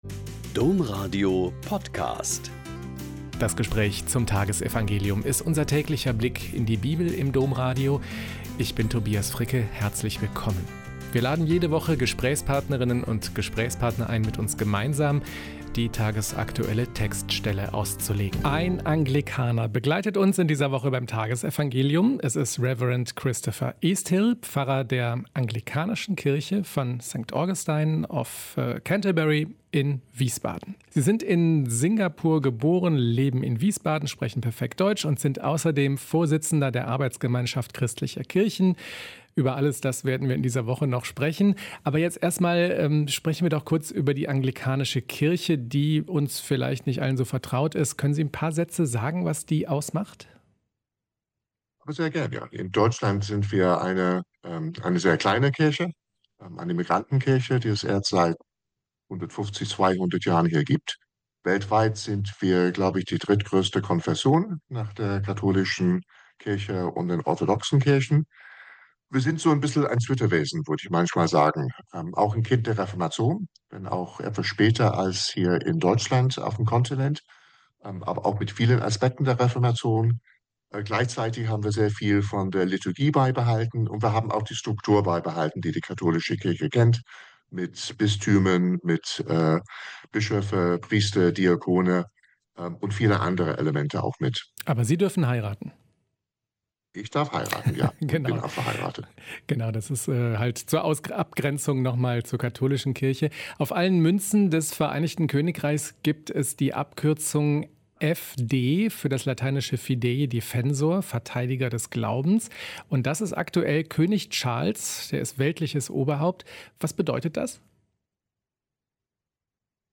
Mt 12,38-42 - Gespräch